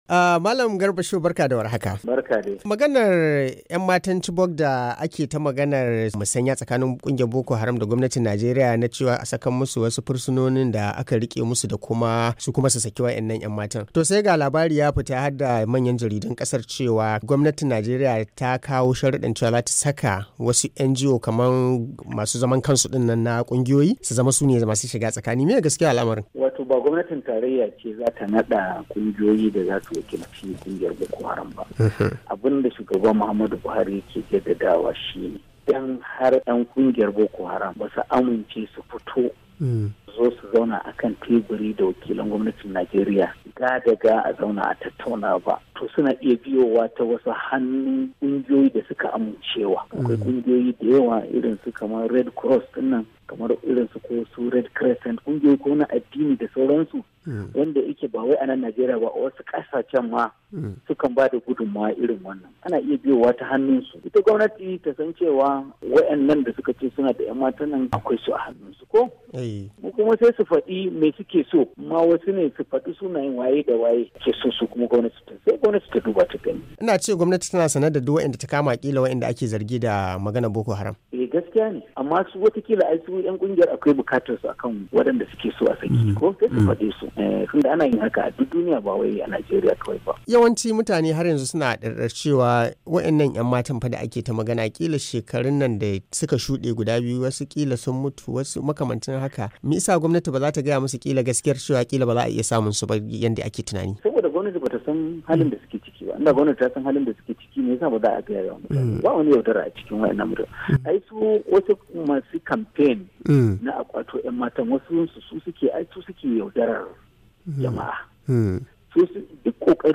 Saboda samun sahihin gaskiyar maganar Muryar Amurka ta zanta da kakakin shugaban kasar Najeriya Malam Garba Shehu.